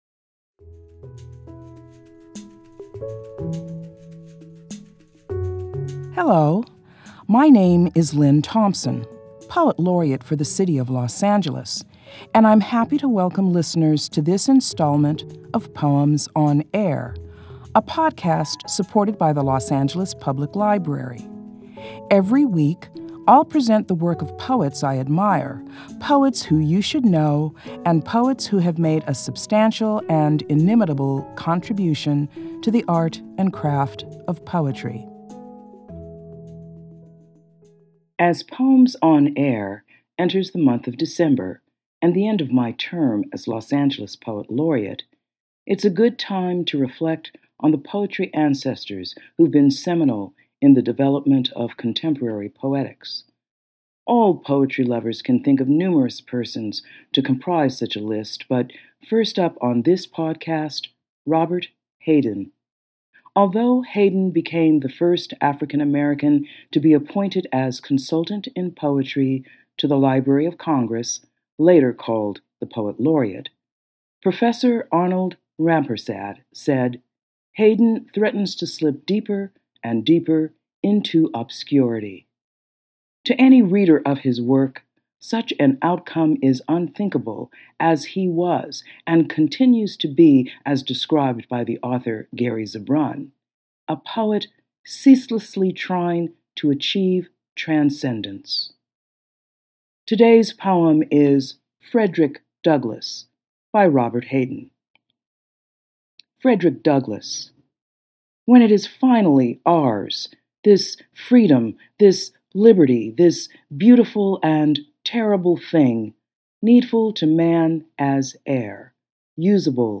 Los Angeles Poet Laureate Lynne Thompson reads "Frederick Douglass" by Robert Hayden.